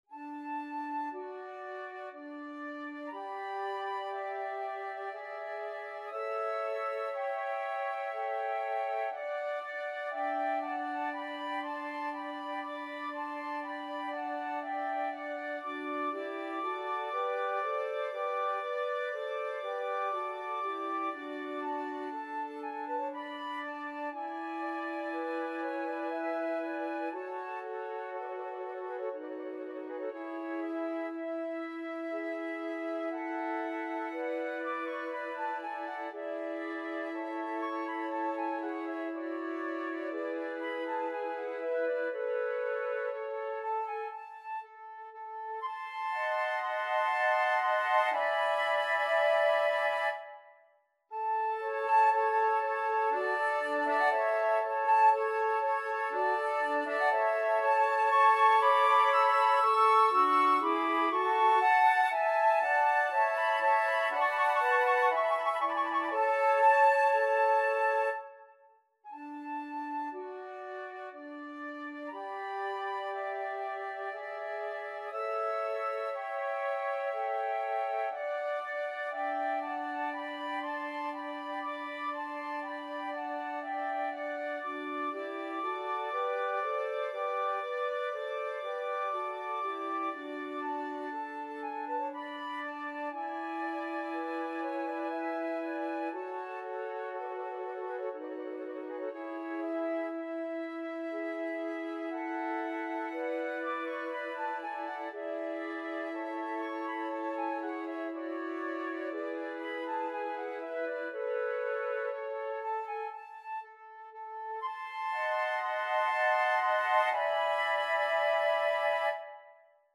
Key: D major (Sounding Pitch)
Time Signature: 3/4
Tempo Marking: Adagio
Style: Classical